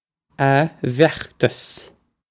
ah vyokh-tos
This is an approximate phonetic pronunciation of the phrase.